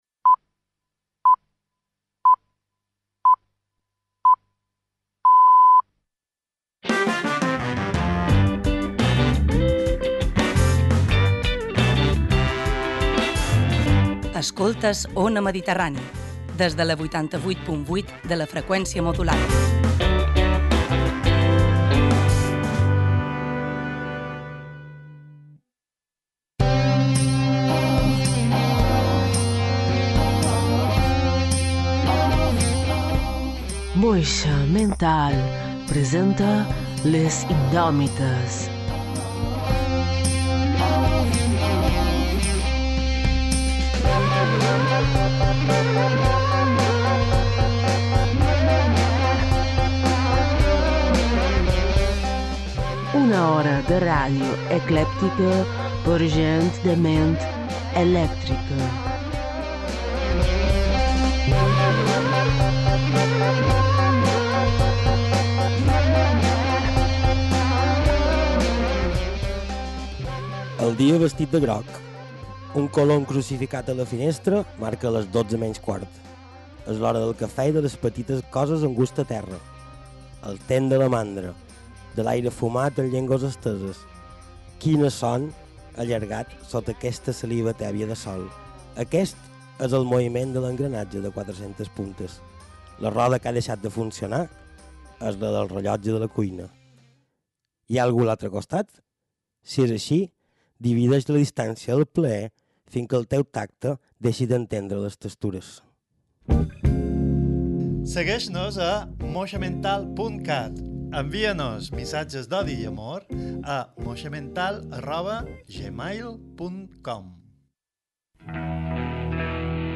El podcast en català mallorquí més caòtic i irreverent d’Ona Mediterrània (88.8FM).